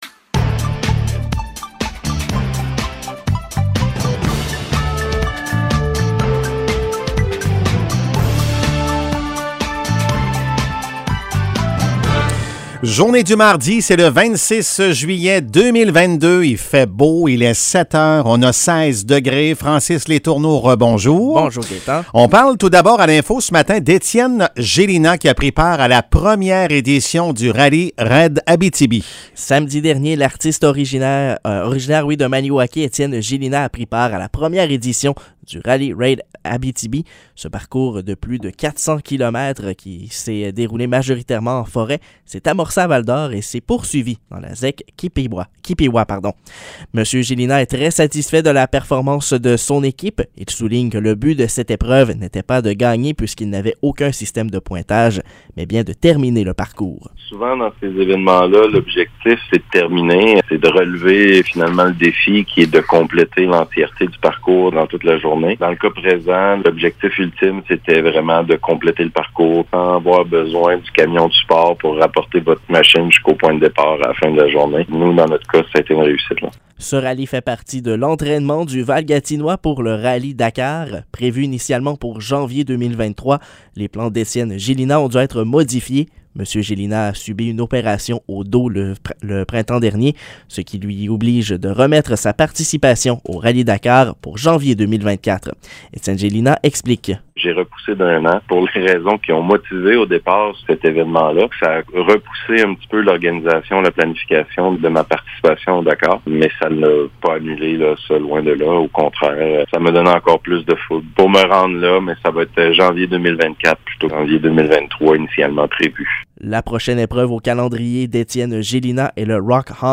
Nouvelles locales - 26 juillet 2022 - 7 h